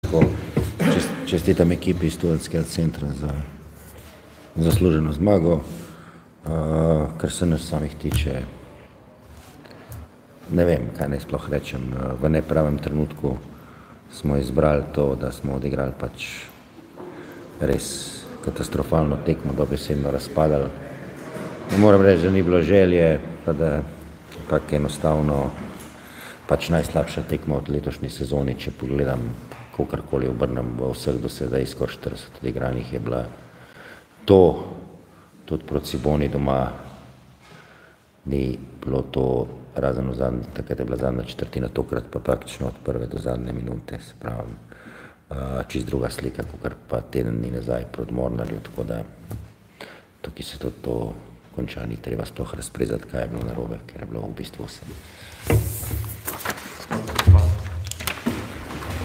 Izjavi po tekmi: